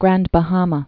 (grănd bə-hämə)